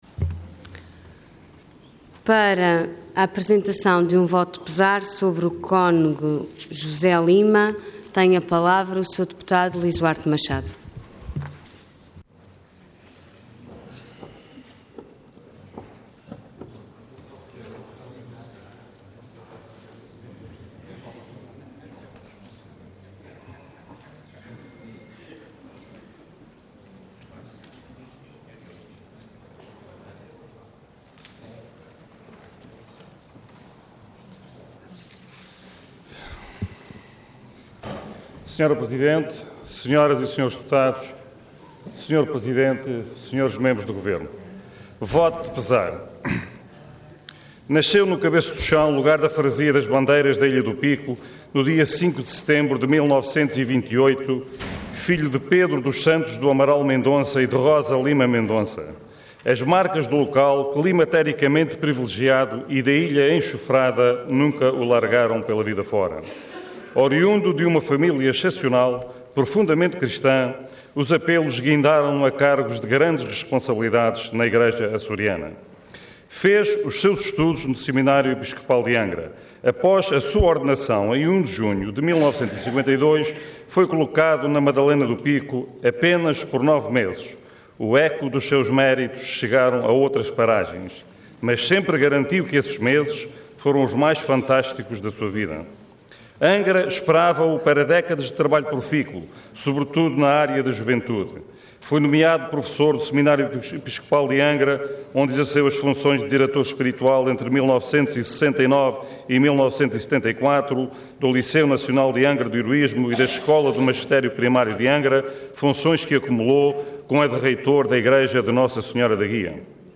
Detalhe de vídeo 17 de janeiro de 2013 Download áudio Download vídeo Diário da Sessão Processo X Legislatura Falecimento do Monsenhor José de Lima do Amaral Mendonça. Intervenção Voto de Pesar Orador Lizuarte Machado Cargo Deputado Entidade PS